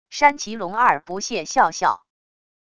山崎龙二不屑笑笑wav音频